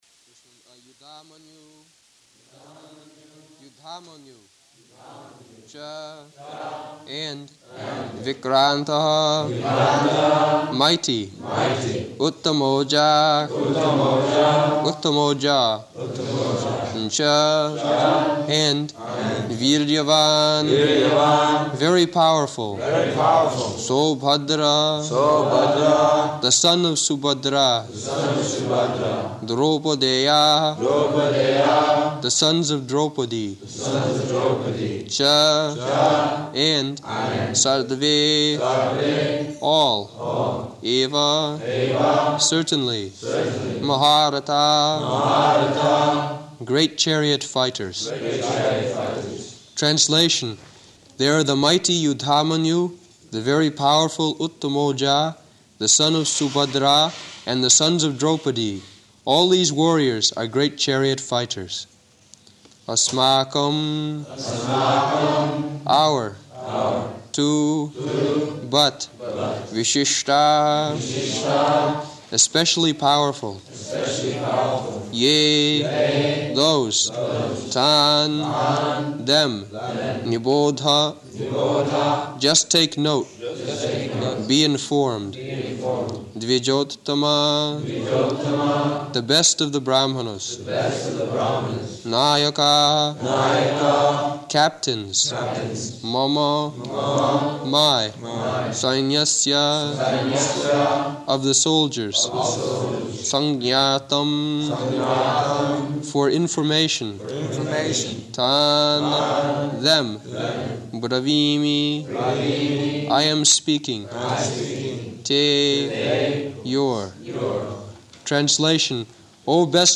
Location: London